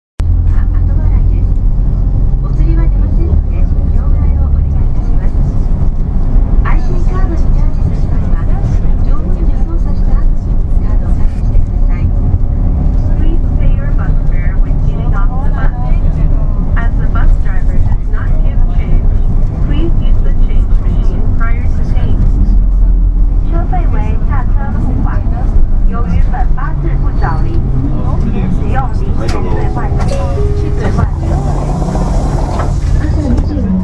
音声合成装置  クラリオン(ディスプレイ１)